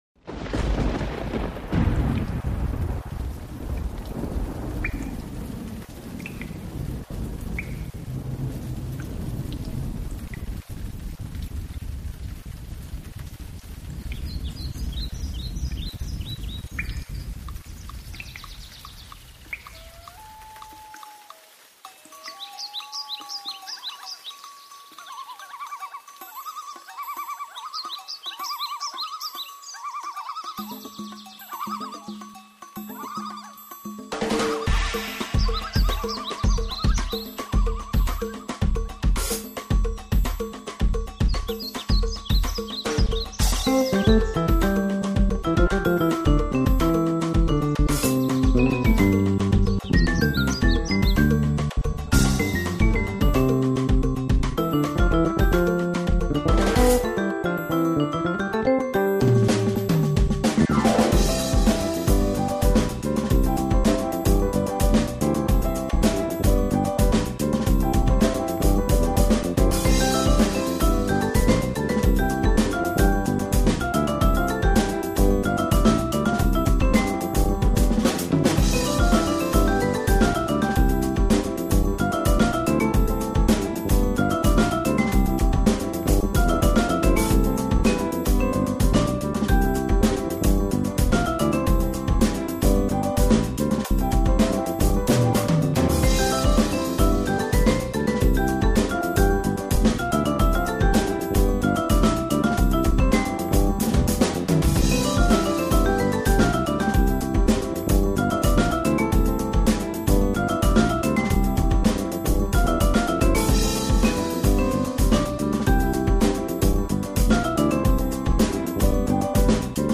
版本: Fusion融合